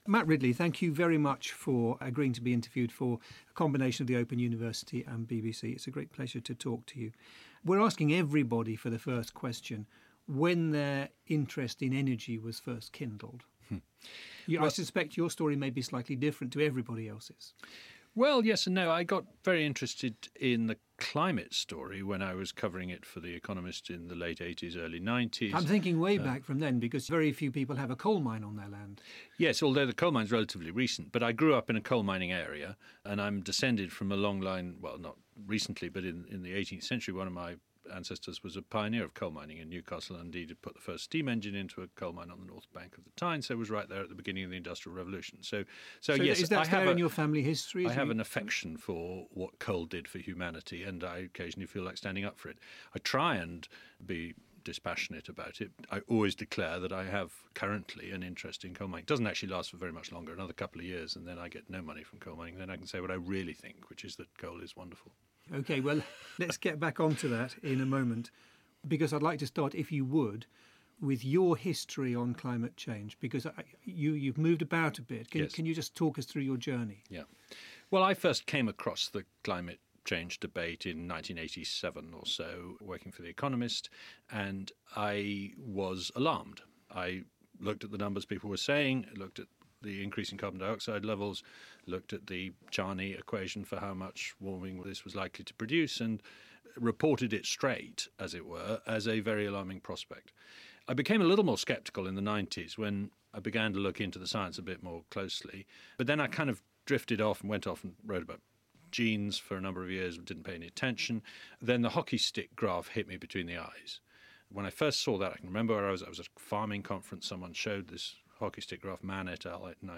Interview with Lord Matthew Ridley